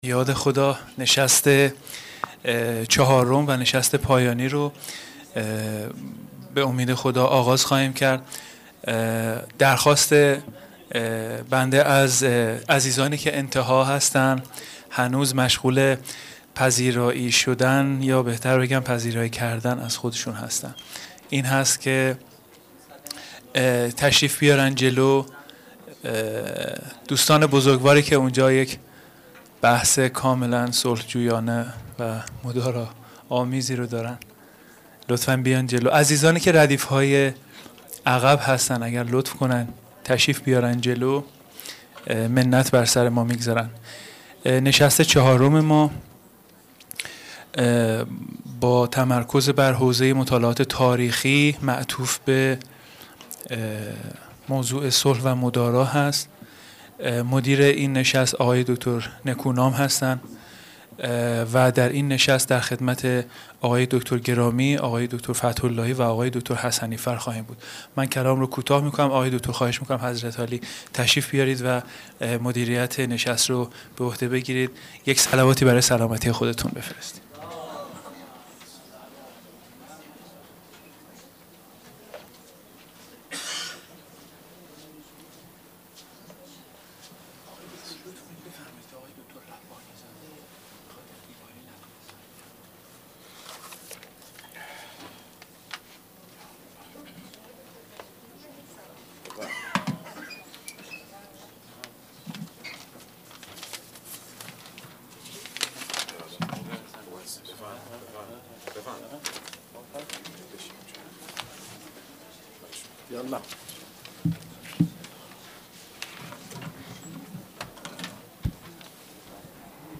سخنرانی
سالن حکمت